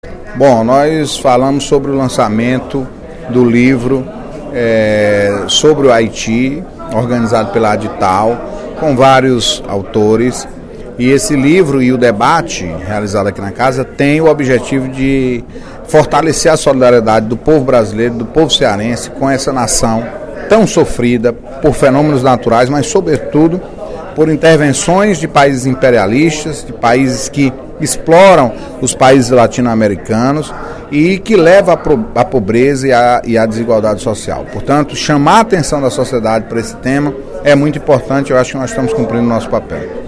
Durante o primeiro expediente desta quinta-feira (14/03), o deputado Antonio Carlos (PT) comunicou que, hoje, a partir das 15h30, será realizada na Casa uma audiência pública para discutir o tema “Haiti: a reconstrução de uma nação soberana”.